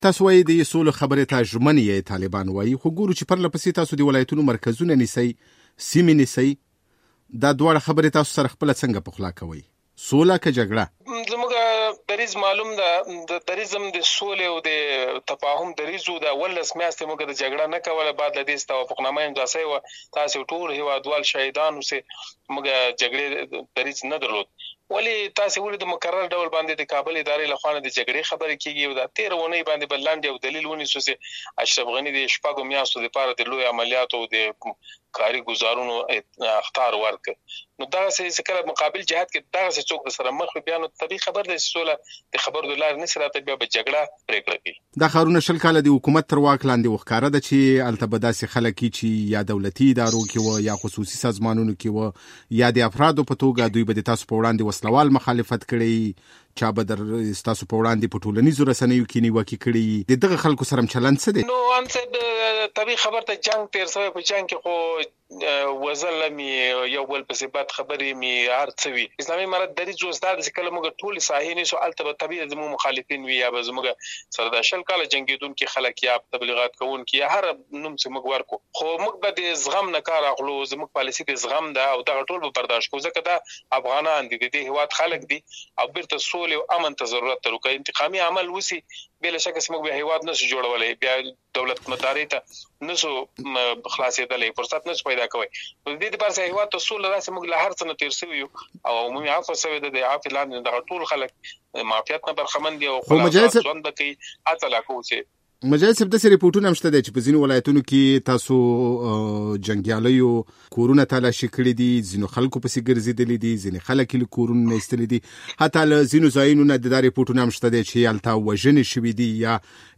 د طالبانو د ویاند ذبیح الله مجاهد سره د دوی تر واک لاندې سیمو کې د وضیعت په اړه مرکه